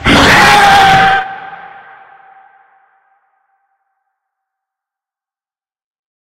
attack_hit_5.ogg